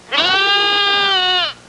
Goats Sound Effect
Download a high-quality goats sound effect.
goats.mp3